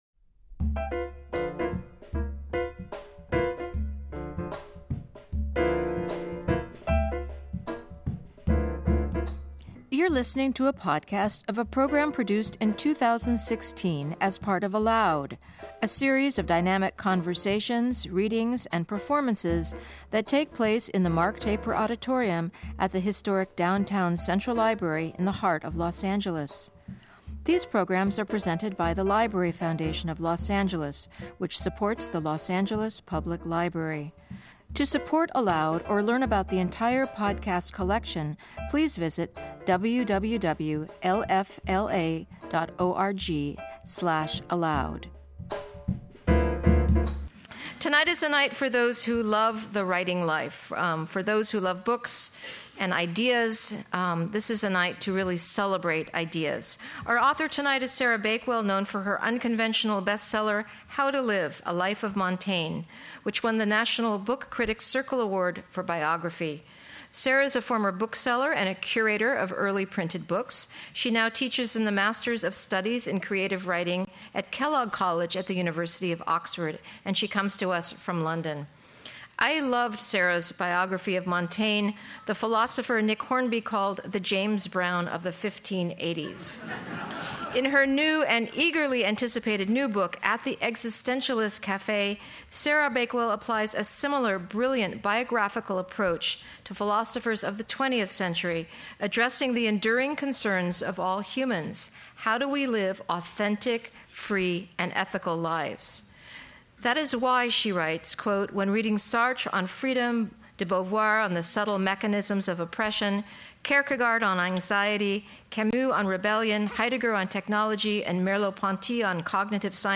In Conversation With David L. Ulin